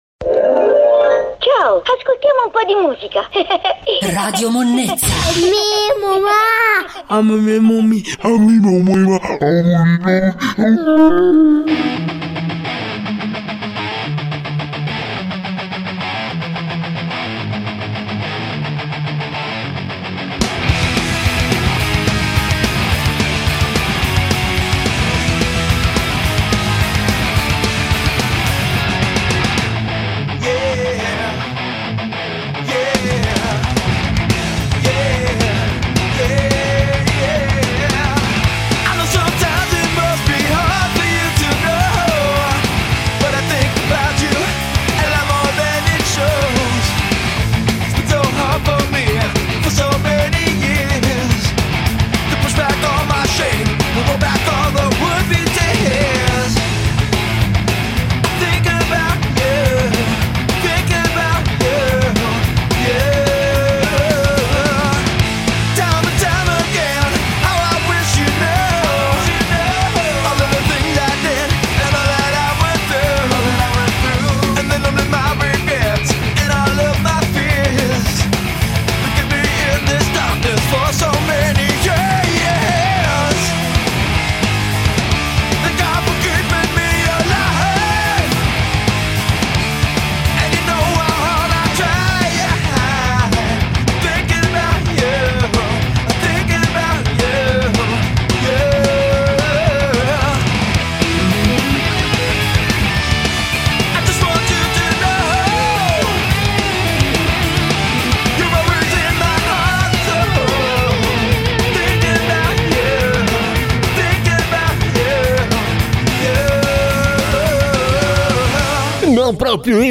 Il dilettantismo tipico del Dj’s che vorrebbe ma non può, impera anche in questa puntata.